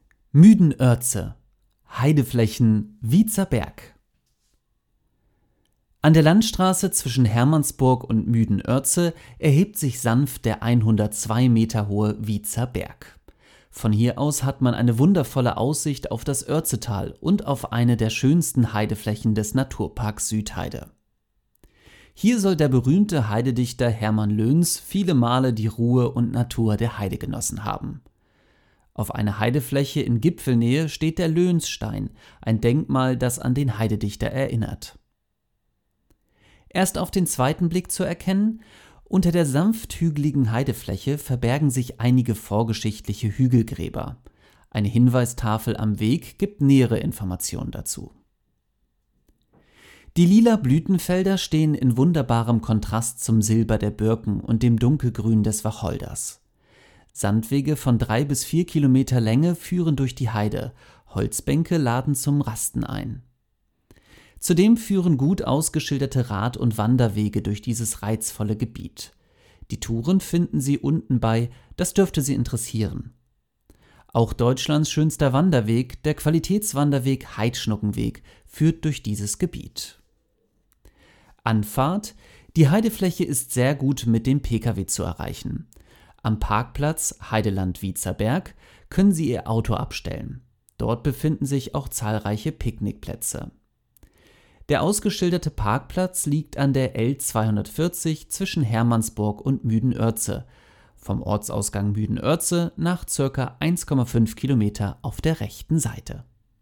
wietzer-berg-text-vorlesen-lassen.mp3